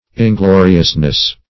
Ingloriousness \In*glo"ri*ous*ness\, n. The state of being inglorious.